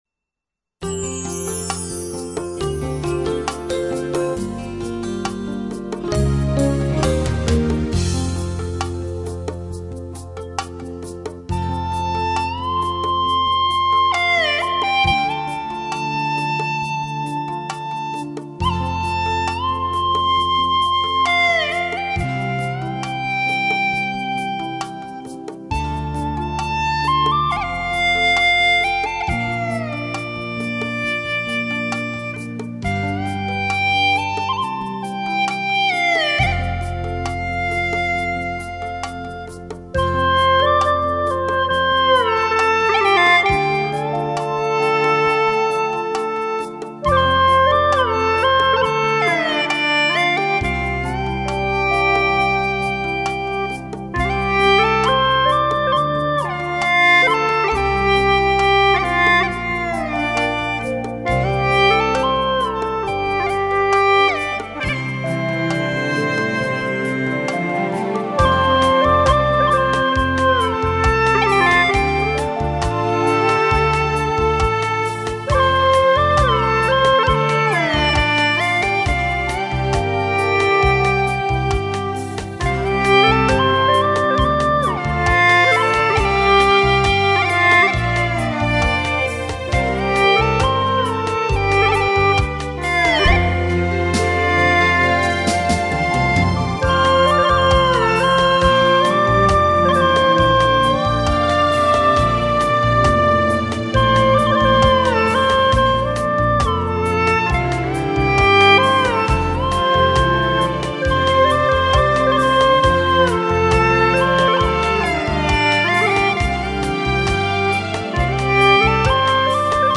调式 : F 曲类 : 独奏